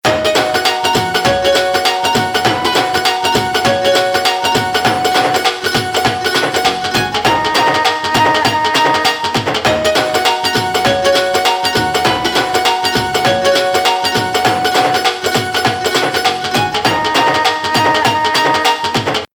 31. bhangra